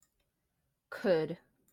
could /kʊd/